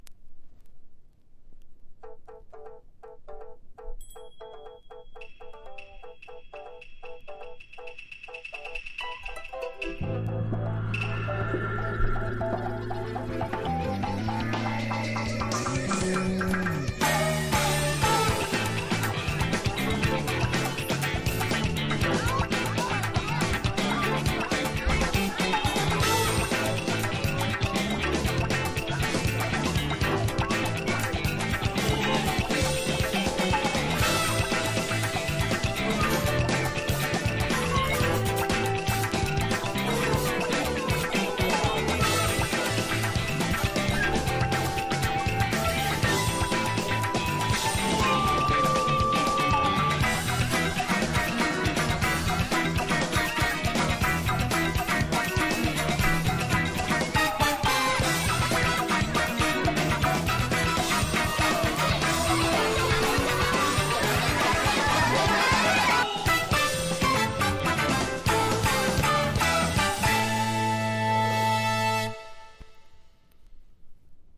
POP
アイドル